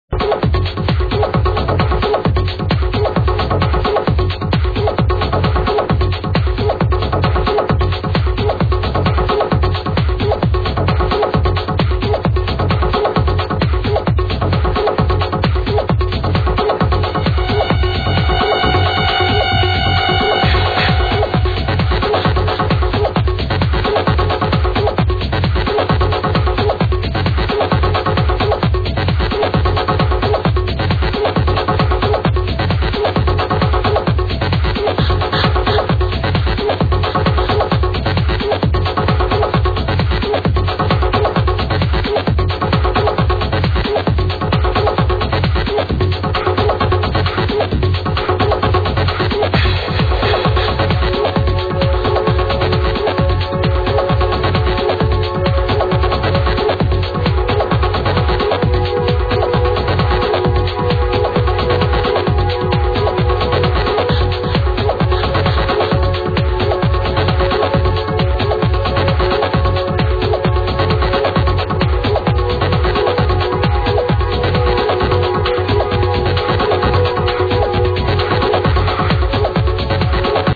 Smile Progressive Song....NEEDS ID!